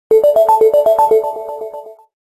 Category : SMS